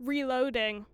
Voice Lines / Barklines Combat VA
LEo Reloading.wav